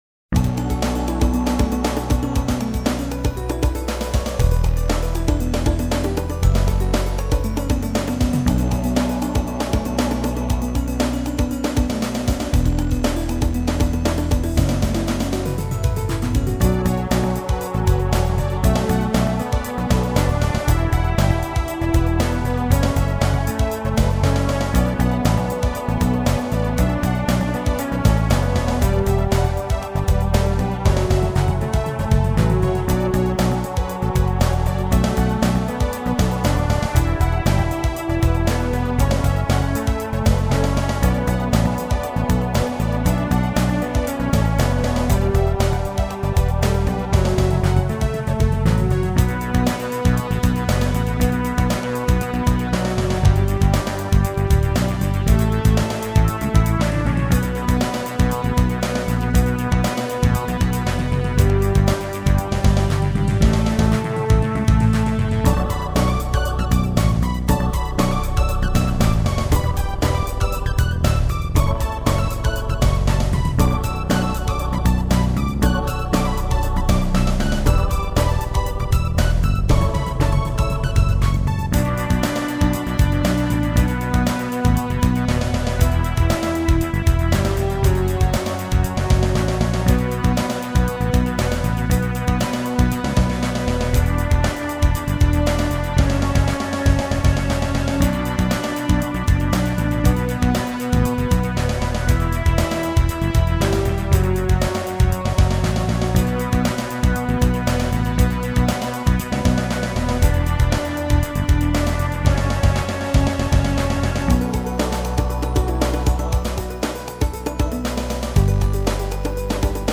今風っぽさ(当時)を出すため、無理矢理テクノっぽい音が入ってるのはご愛嬌です。
※音源はＳＣ８８Ｐｒｏ。